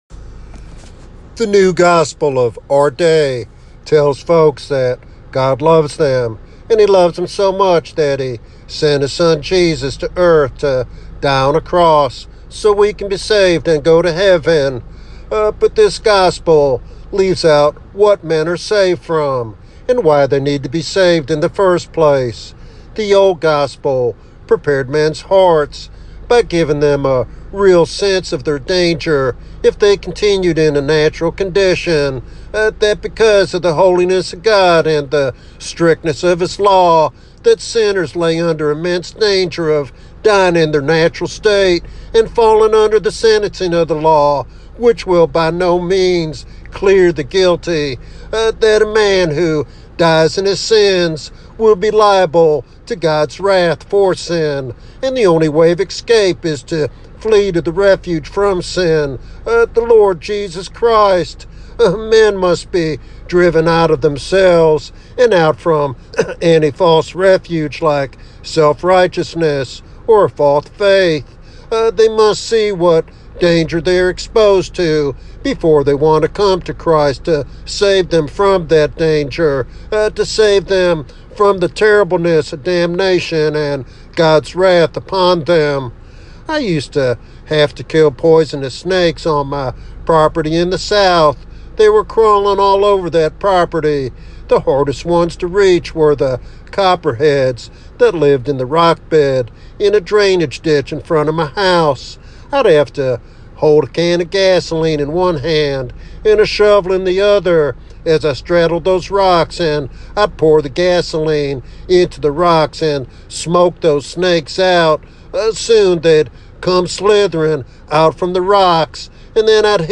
This message is a passionate plea for genuine repentance and revival in the church.
Sermon Outline